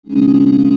mechanicalMining
arm.wav